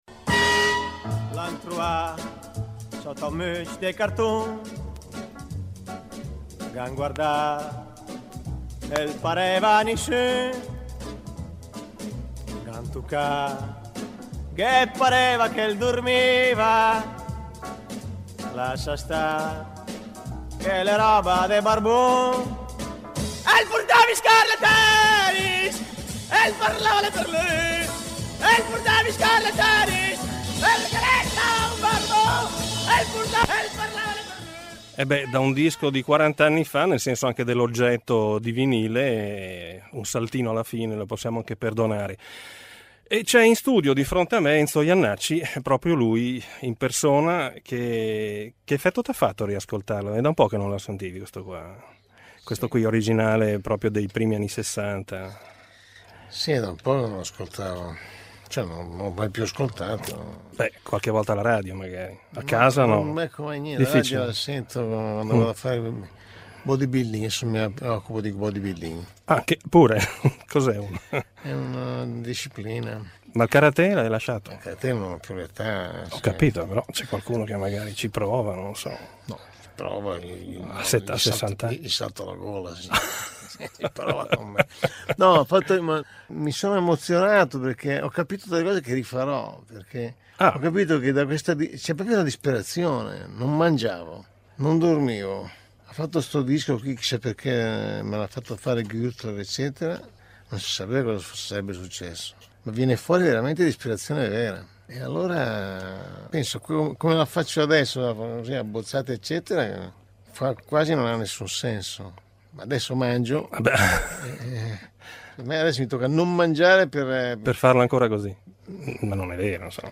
Una chiacchierata a tutto tondo in cui Jannacci passa in rassegna alcuni momenti della sua vita privata ed artistica sul filo dei ricordi e col suo inconfondibile e indimenticabile stile.